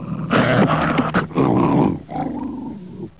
Grunt Sound Effect
Download a high-quality grunt sound effect.
grunt-1.mp3